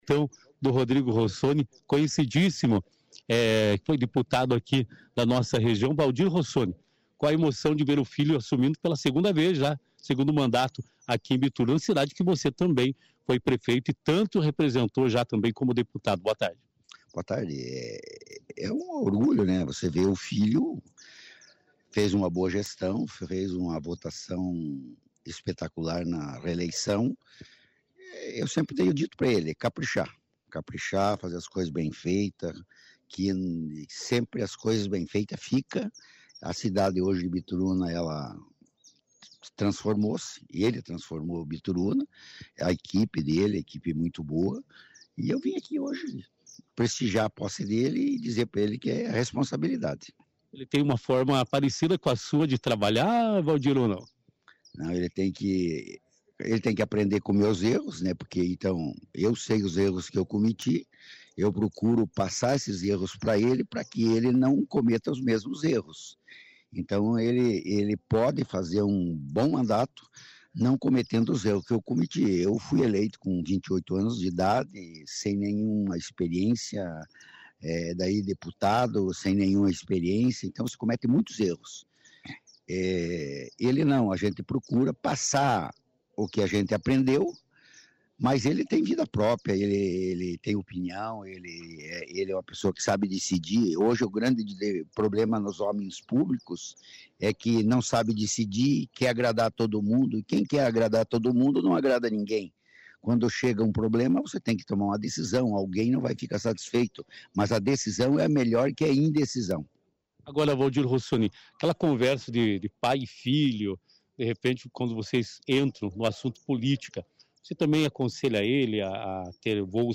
O ex Deputado Valdir Rossoni, pai do prefeito Rodrigo, também conversou com a nossa equipe e disse que apesar de ter se aposentado da vida política, aconselhou seu filho para que ele tome as melhores decisões.